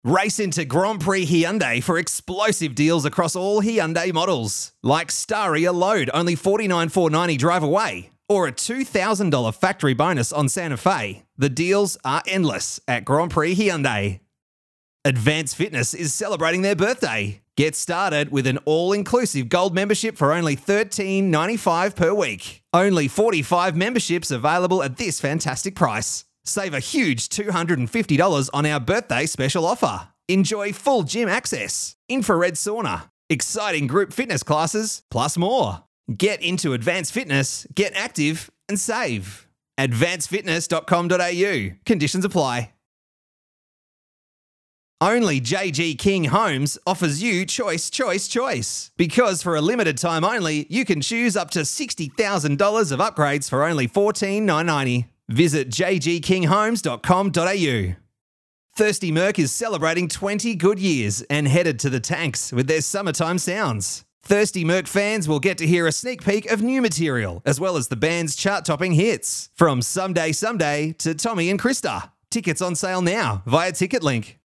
Male
Relatable, conversational male Australian voice.
Natural, friendly, warm, approachable, affable, every-man style Australian voice.
Radio Commercials
Hard Sell, Radio Tv Commercials